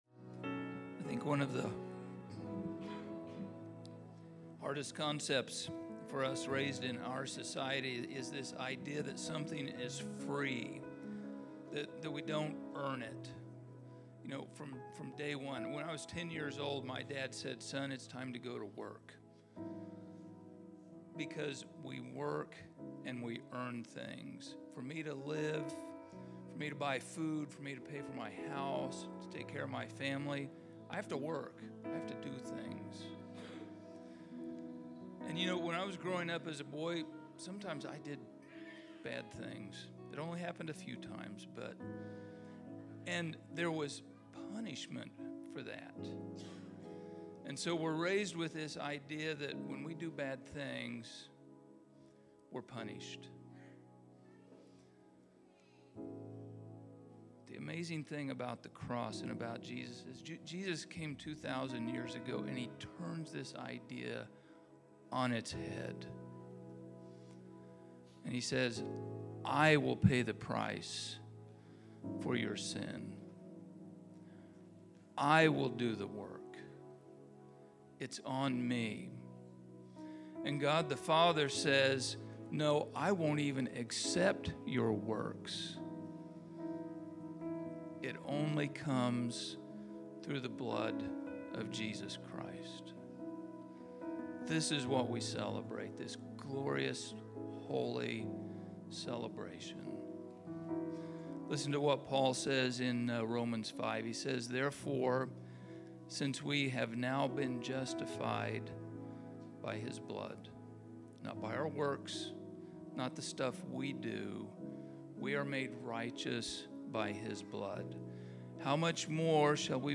Communion Meditation
El Dorado, Kansas El Dorado Equip